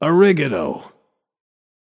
Shopkeeper voice line - Arih-gatoh.